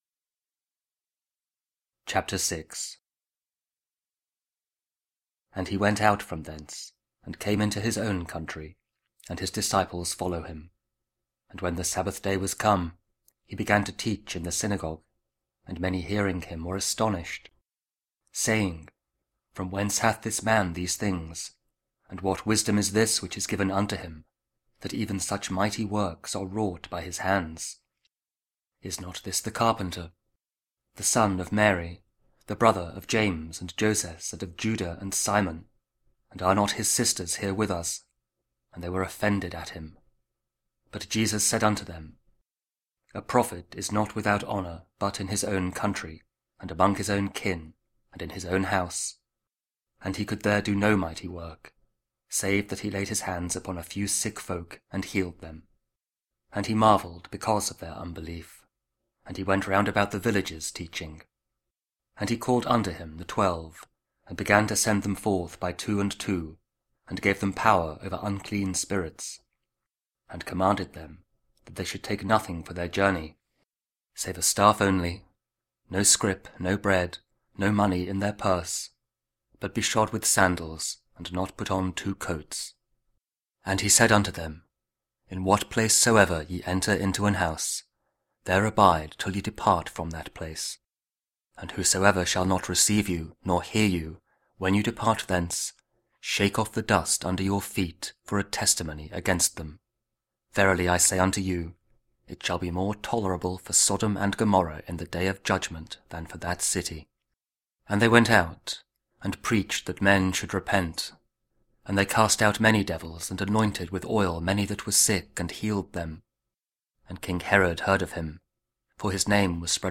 Mark 6: 45-52 – Wednesday after Epiphany or 9th Jan. (Audio Bible, KJV, Spoken Word)